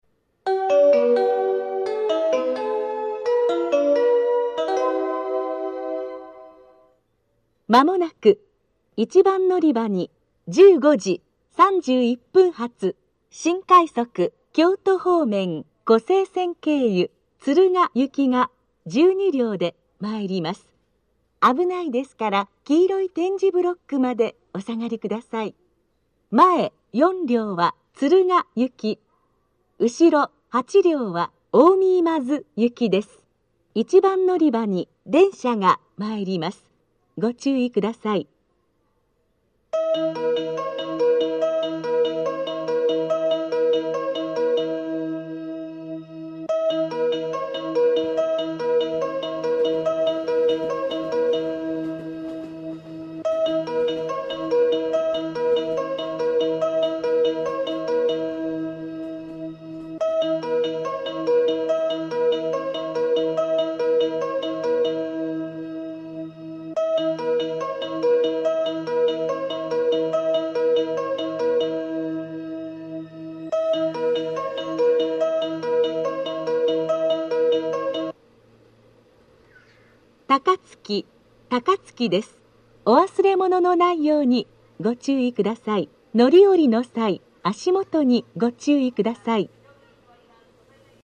（女性）
接近放送・到着放送